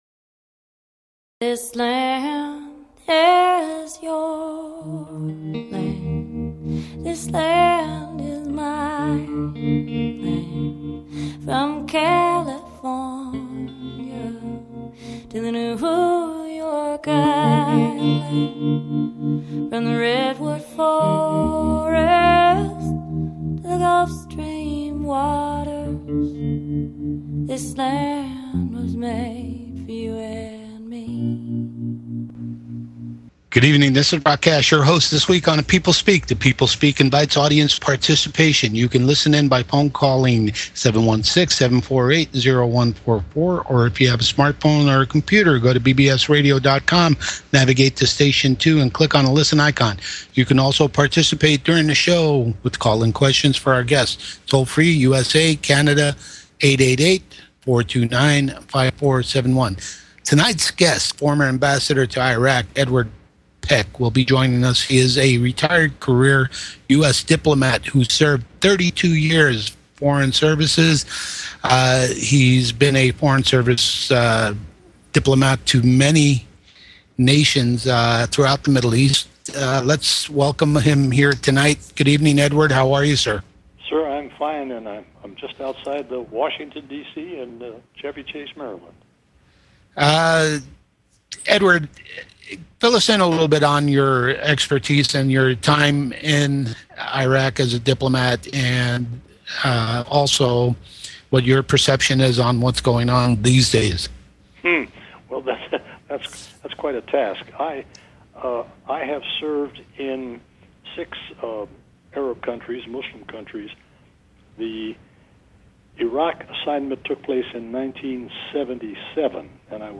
Guest, Edward Peck